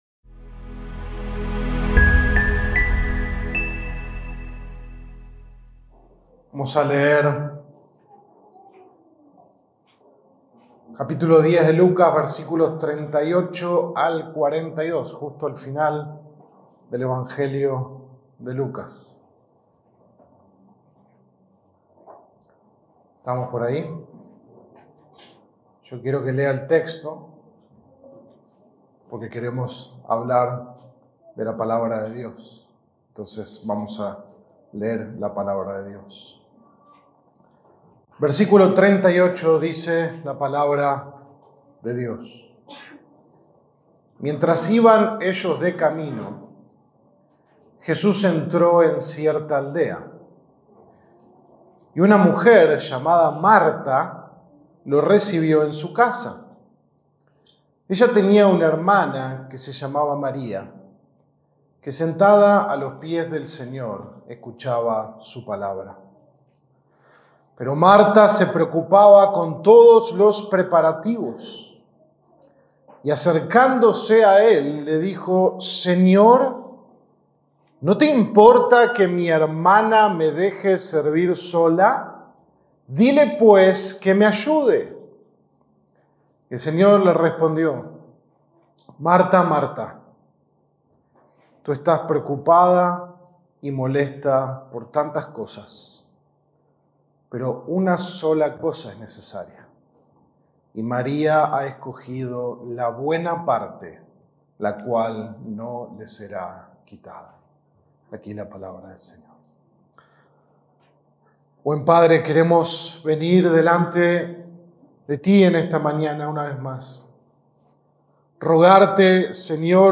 Audio del sermón
Sermon-23-de-noviembre.mp3